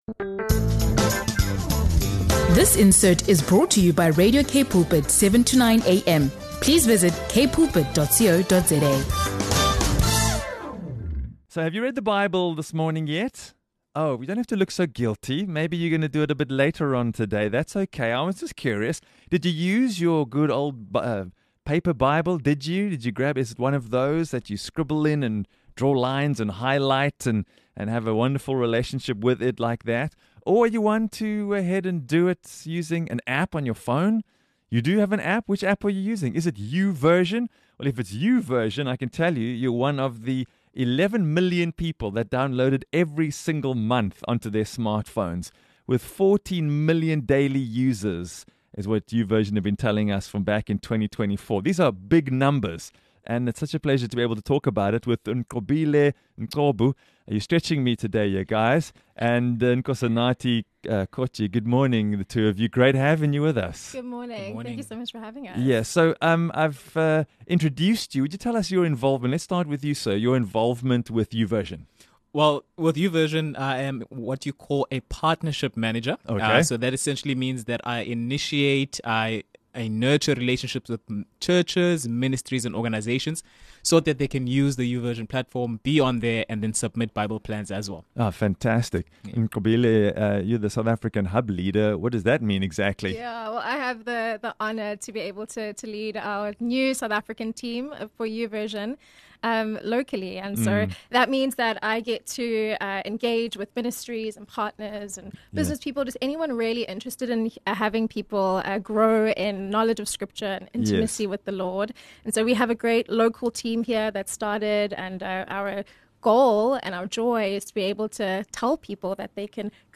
The YouVersion Bible app, one of the world’s most popular digital scripture platforms, is set to reach an incredible milestone of one billion installs globally. In a Radio K Pulpit interview